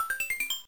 Sound effect of 1-Up in Super Mario World: Super Mario Advance 2